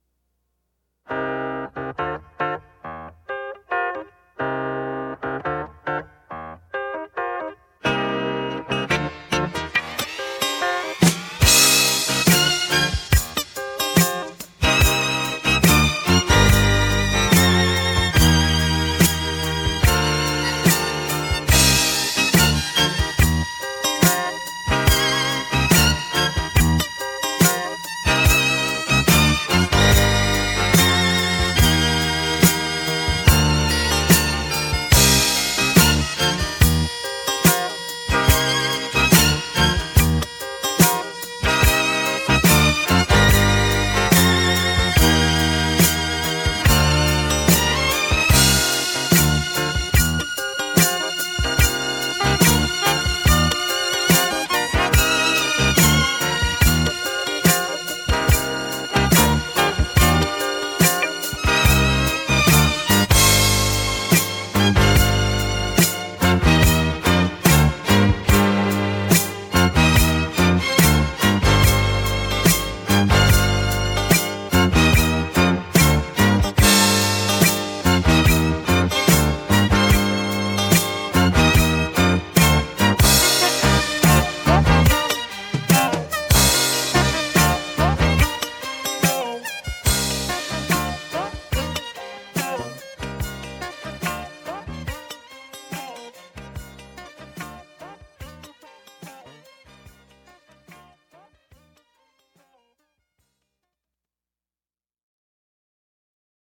Mp3 demos of a few below.(Wav files will be better quality)